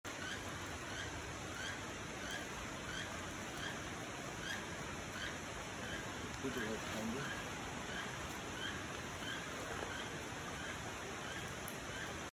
We think those are frogs.
LaFortunaForestSounts02.mp3